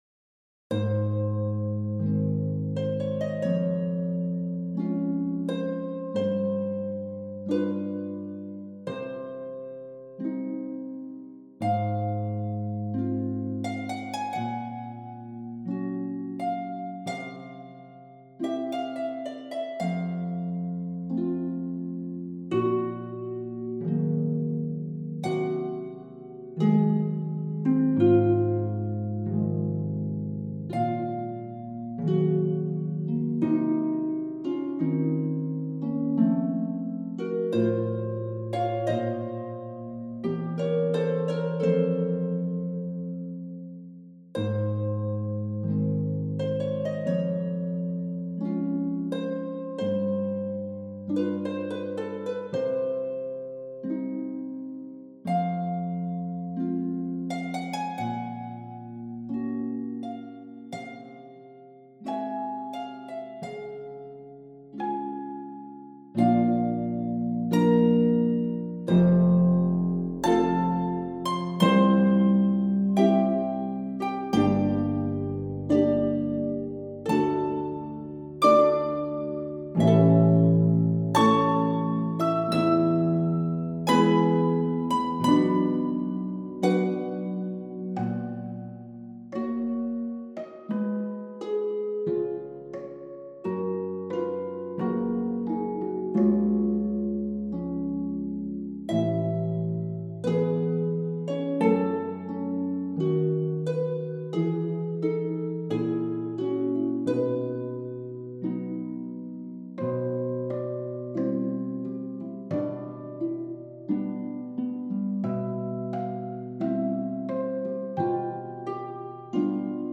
for four pedal harps.